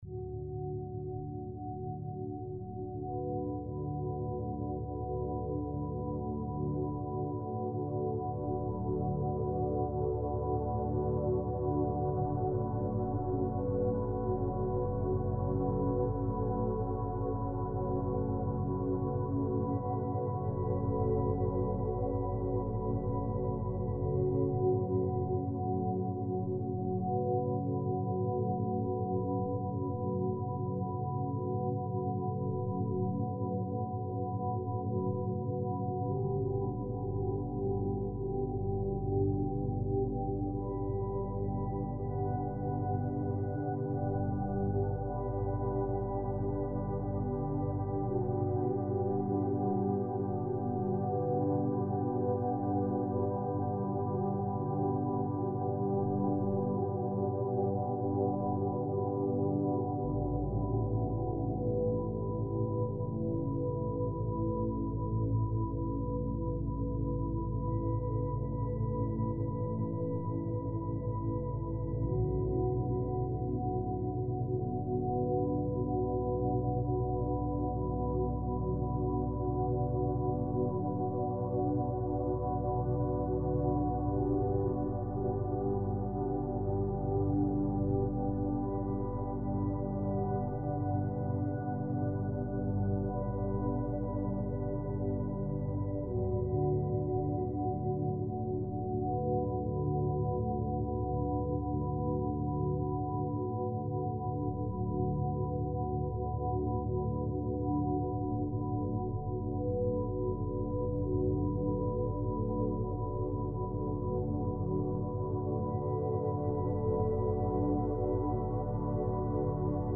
22 Hz - Beta Binaural Beats - A Gentle Push Toward Focus ~ Binaural Beats Meditation for Sleep Podcast
Beschreibung vor 4 Monaten Mindfulness and sound healing — woven into every frequency.